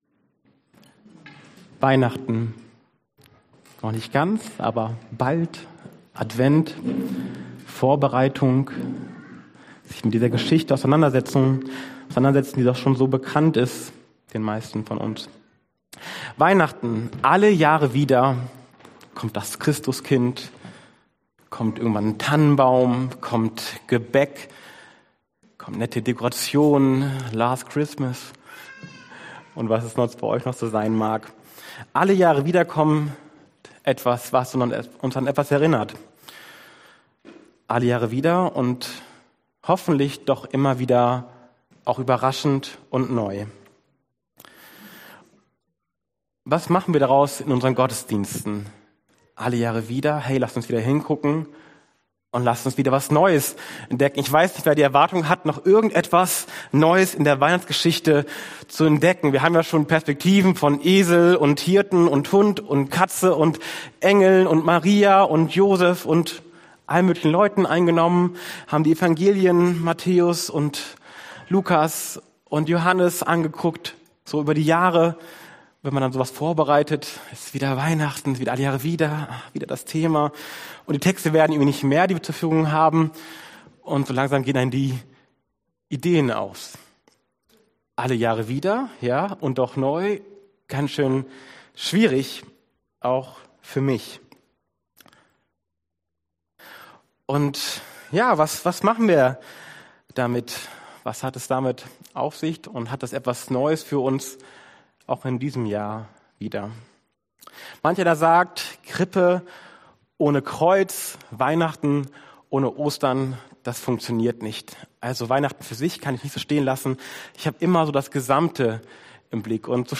alle Jahre wieder und doch neu Passage: Johannes 1,1-5.14 Dienstart: Predigt Themen